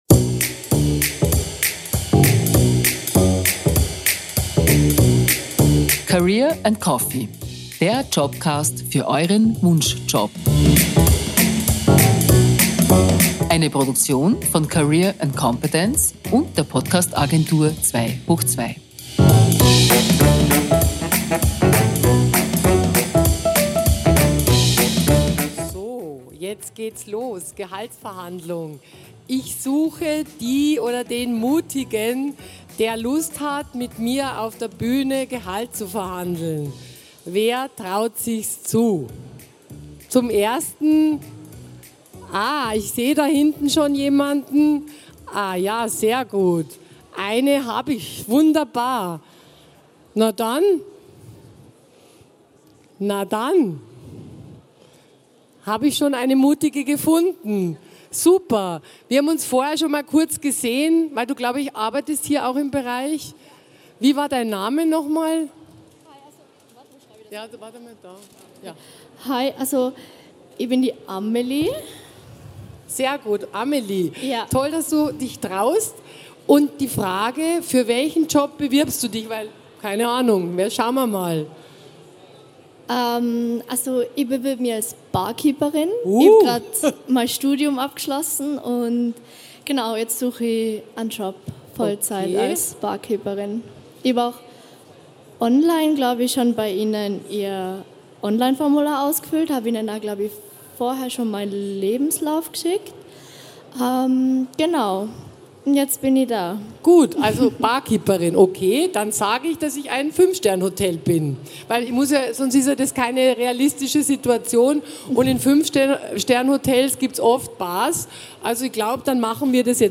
So wird Deine nächste Gehaltsverhandlung bestimmt ein Erfolg. Livemitschnitt von der career & competence 2023 in Innsbruck, am 26. April 2023.